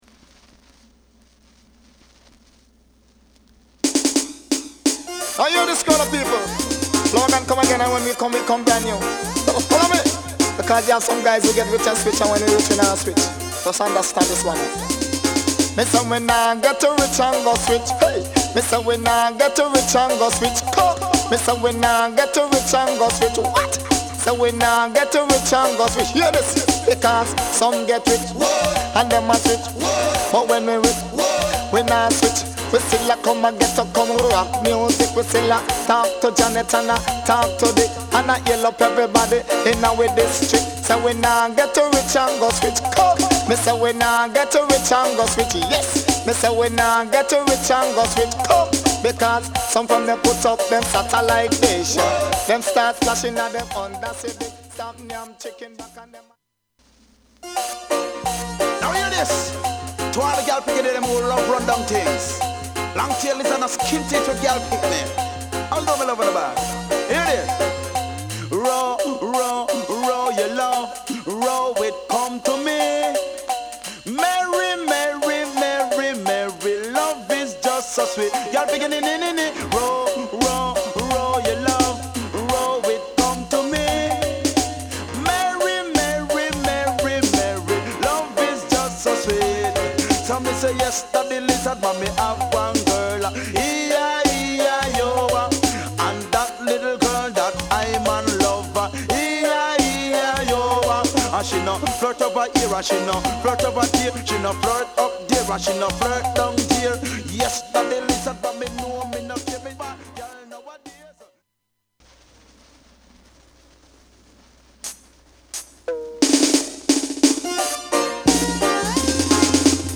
REGGAE / DANCEHALL